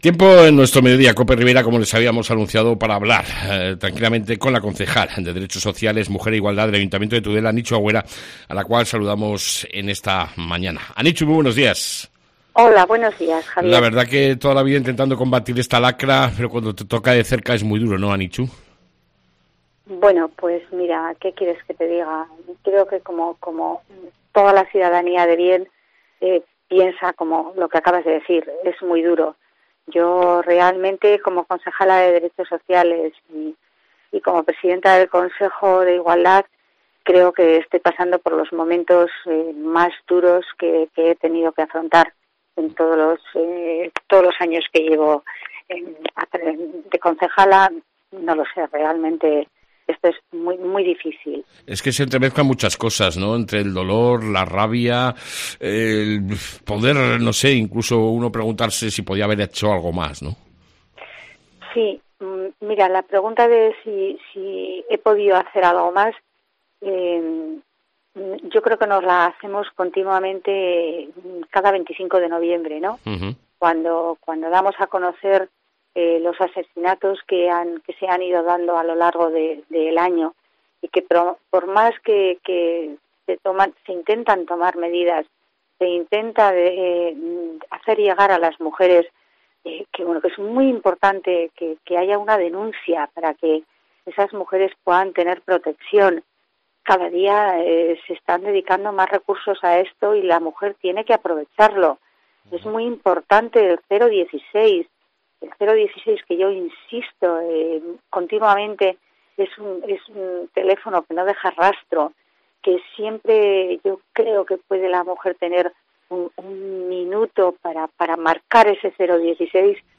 AUDIO: Hablamos con la concejal de Bienestar Social Mujer e Igualdad Anichu Agüera Angulo tras el drama ocurrido en Tudela.